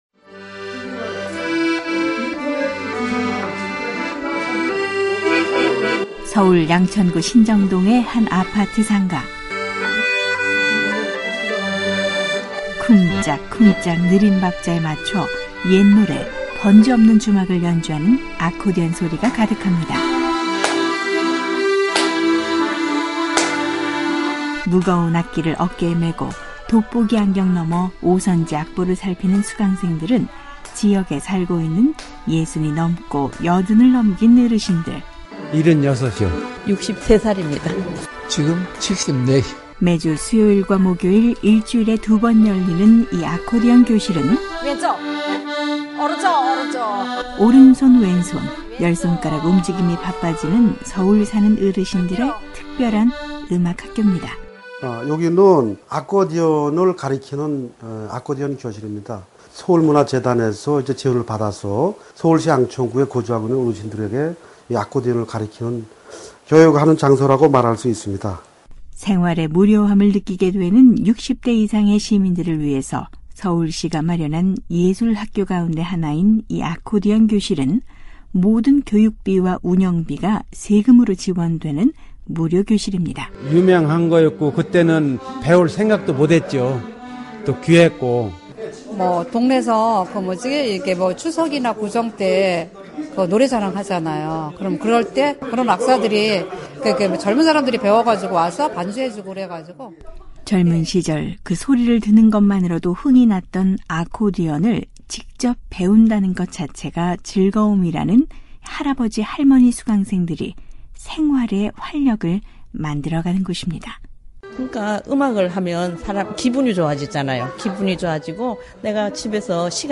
오늘은 서울 양천구에 있는 한 아코디언교실을 찾아가 보겠습니다. 무거운 아코디언을 어깨에 메고 오선지 음표를 따라 소리를 내는 아코디언 교실 학생들은 60대~80대 어르신들. 배우는 학생들도 특별하지만 가르치는 선생님도 특별한 이 아코디언 교실의 풍경이 아주 정겹다고 합니다.